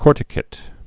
(kôrtĭ-kĭt, -kāt) also cor·ti·cat·ed (-kātĭd)